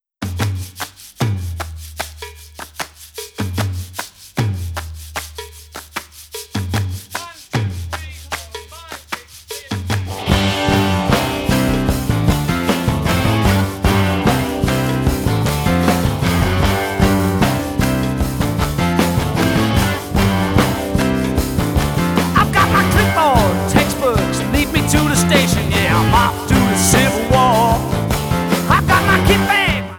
1999 Japan CD (Remix)